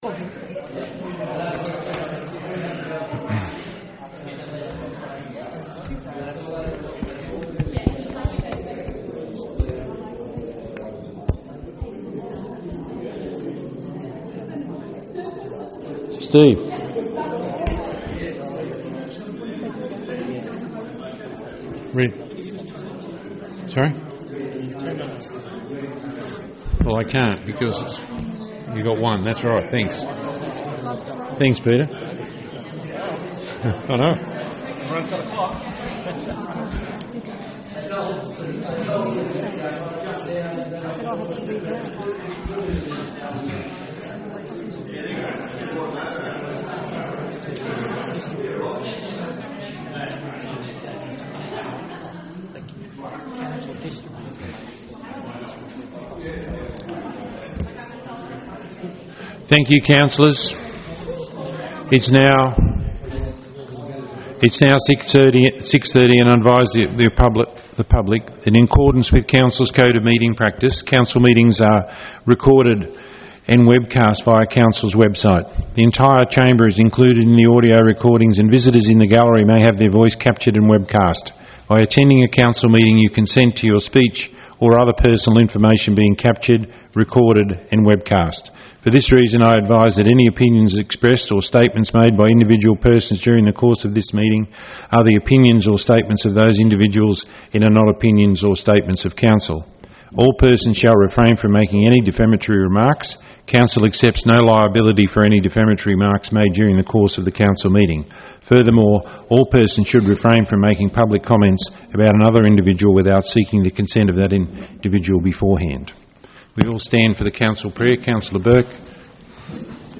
17 March 2021 Ordinary Meeting
Meetings are held in the Council Chambers, 62-78 Vincent Street, Cessnock.